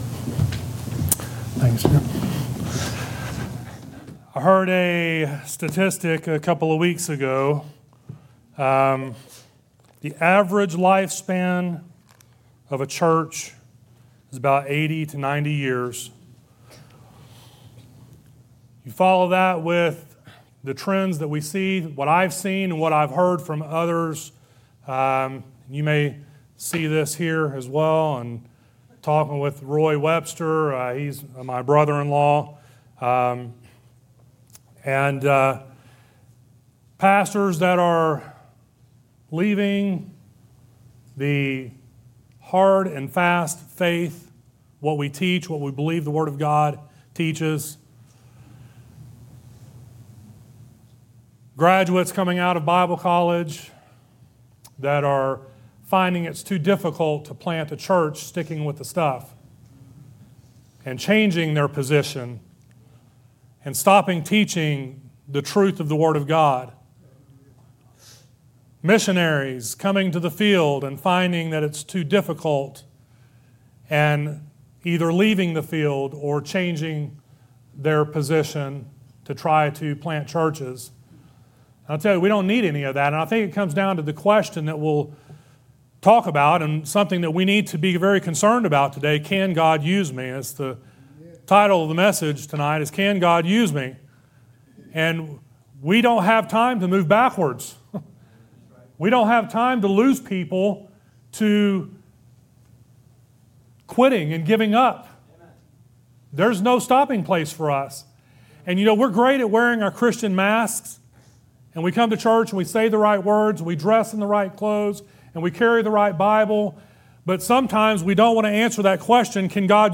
" Guest & Staff Preachers " Guest & Staff Preachers at Bethany Baptist Church Scripture References: Jeremiah 29:11-13